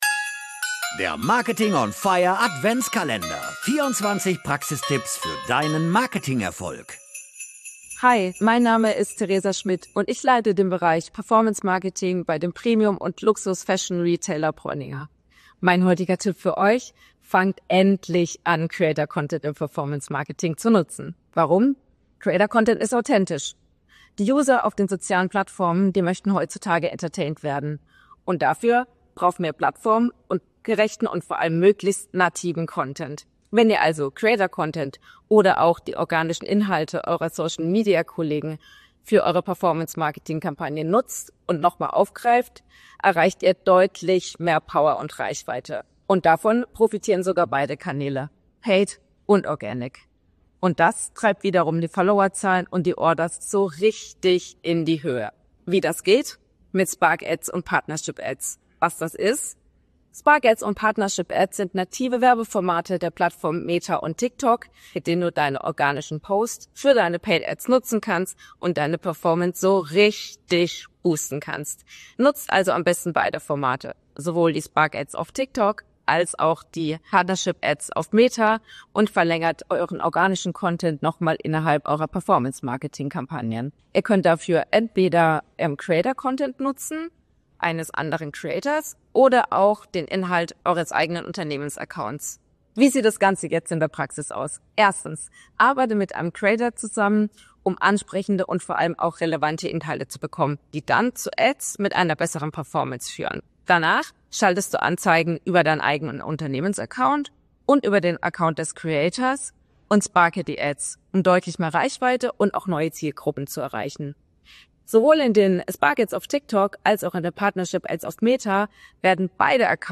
Sie liefern Einblicke in Marketing-Abteilungen, teilen strategische Tipps und nehmen Dich mit in den Marketing-Maschinenraum wo sie verraten, wie sie konkrete Maßnahmen umsetzen.